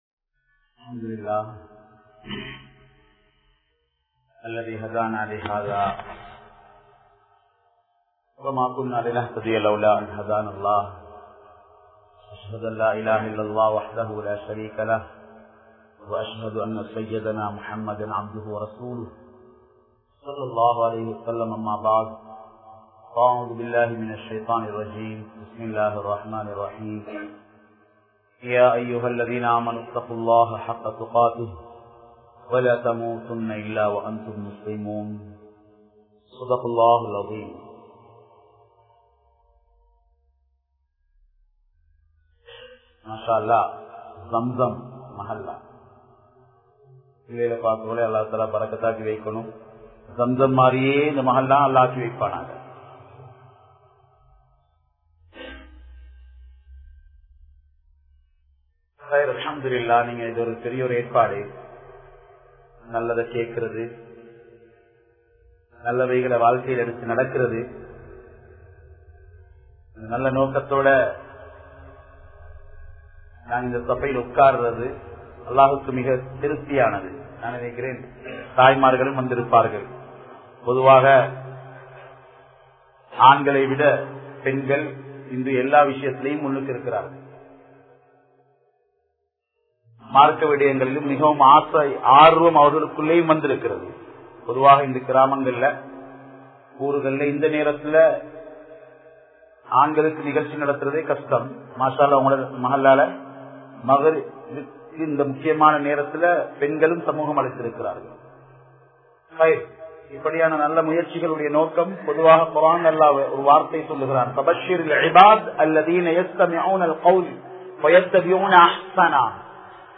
Vekusana Oodakankalin Theemaikal(வெகுசன ஊடகங்களின் தீமைகள்) | Audio Bayans | All Ceylon Muslim Youth Community | Addalaichenai